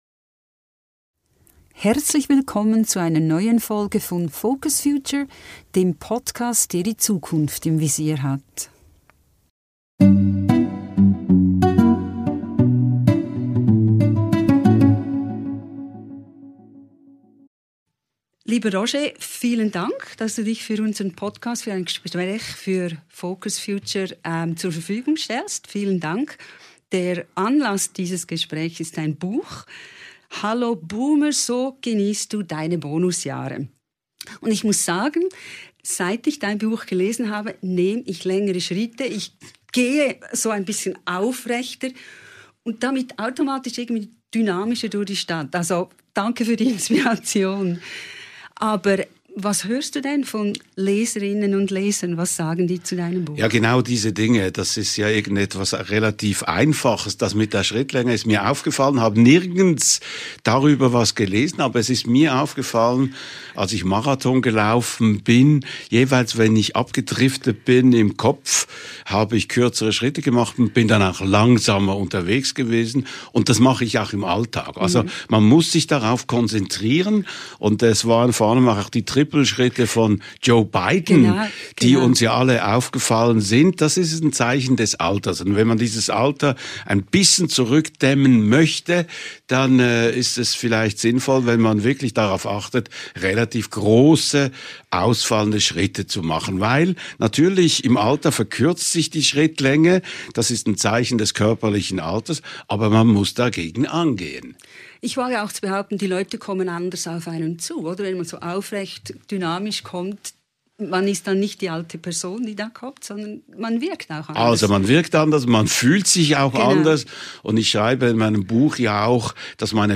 Hört rein in diese Episode mit Roger Schawinski - ein Gespräch, das zeigt, warum Verantwortung für sich selbt die beste Altervorsorge ist.